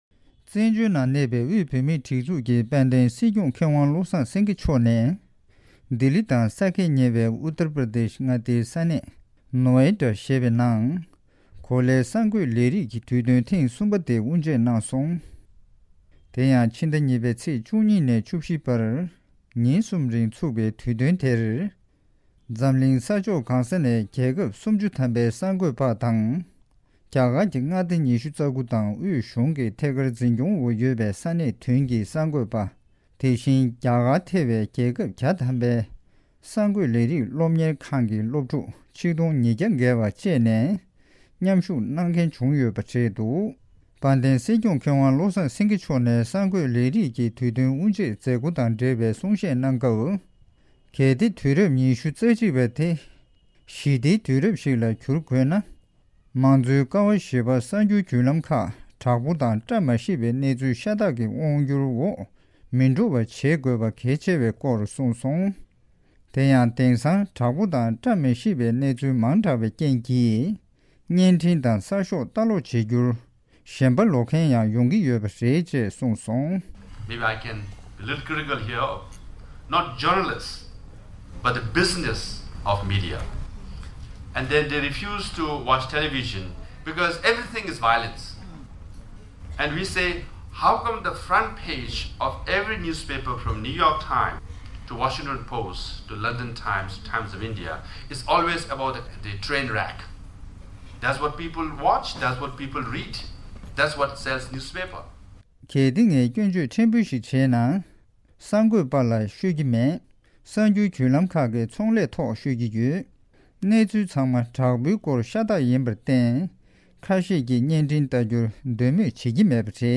སྲིད་སྐྱོང་མཆོག་གི་གོ་ལའི་གསར་འགོད་ལས་རིགས་ཀྱི་དུས་སྟོན་ཐོག་ཏུ་གསུང་བཤད།
དཔལ་ལྡན་སྲིད་སྐྱོང་མཁས་དབང་བློ་བཟང་སེང་གེ་མཆོག་གིས་རྒྱ་གར་གྱི་རྒྱལ་ས་ལྡི་ལི་དང་ཉེ་འཁོར་གྱི་ནྷོལ་ཌ་གྲོང་ཁྱེར་དུ་ཚོགས་པའི་གོ་ལའི་གསར་འགོད་ལས་རིགས་ཀྱི་དུས་སྟོན་ཐེངས་གསུམ་པ་དབུ་འབྱེད་ཐོག་ཏུ་གསུང་བཤད་གནང་ཡོད་ཅིང་། དུས་སྟོན་དེ་ནི་ཕྱི་ཟླ་༢་པའི་ཚེས་༡༢་ནས་ཚེས་༡༤་བར་དུ་འཚོགས་རྒྱུ་ཡིན་པ་མ་ཟད། དུས་སྟོན་དེར་འཛམ་གླིང་གི་རྒྱལ་ཁབ་སུམ་བཅུ་ཙམ་དང་རྒྱ་གར་བཅས་ཀྱི་གསར་འགོད་པ་ཁྱོན་ཆེ་ཞིག་མཉམ་ཞུགས་གནང་ཡོད་པ་རེད།